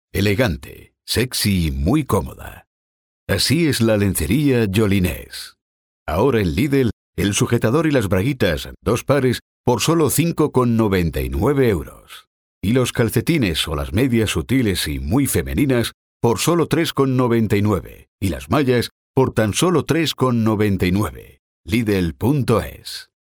I am a native / neutral freelance (non union) EU Spanish/Castilian male Voice Actor and Voice Talent (born/rai...
Warm
Reassuring
Elegant